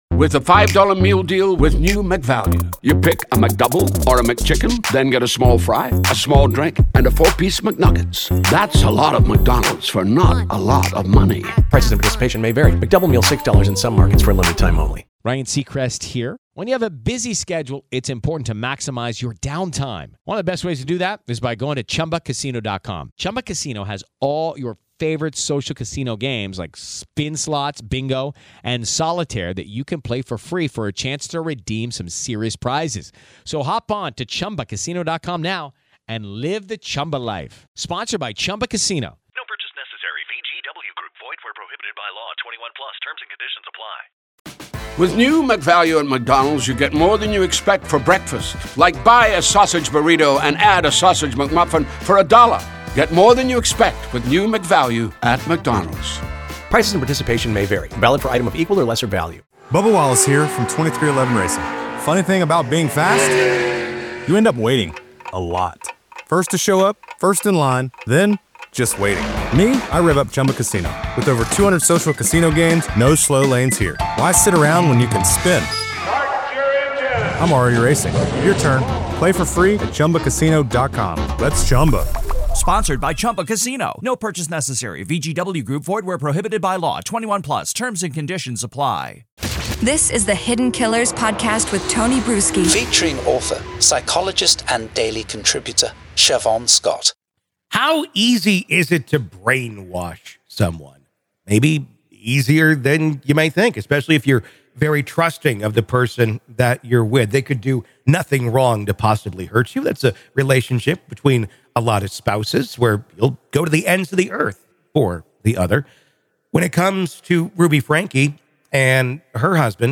Welcome to the "Week in Review," where we delve into the true stories behind this week's headlines.